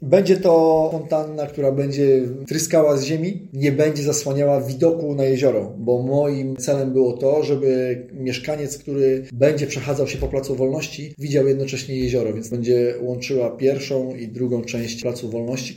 Teraz, jak mówi burmistrz Michał Wiatr, rusza budowa obiektu, który zupełni zmieni charakter placyku przed Biblioteką Miejską.